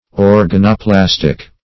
Search Result for " organoplastic" : The Collaborative International Dictionary of English v.0.48: Organoplastic \Or`ga*no*plas"tic\, a. [Organo- + -plastic.] (Biol.) Having the property of producing the tissues or organs of animals and plants; as, the organoplastic cells.